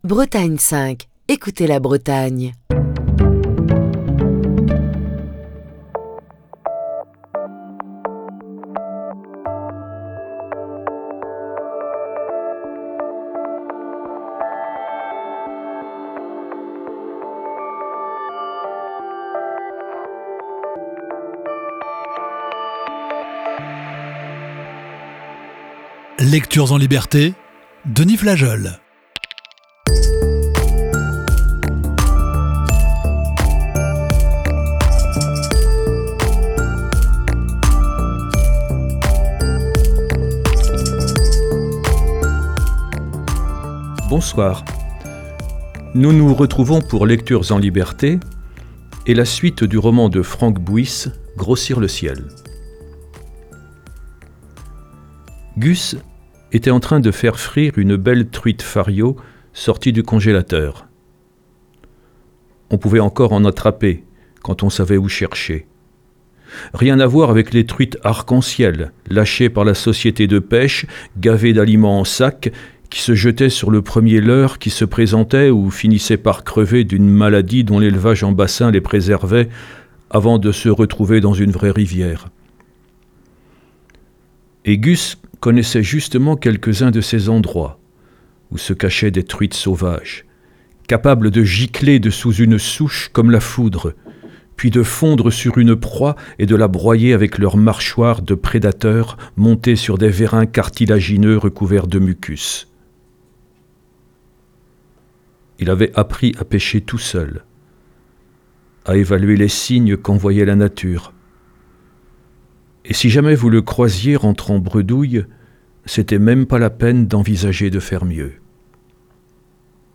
Émission du 2 octobre 2023.